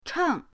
cheng4.mp3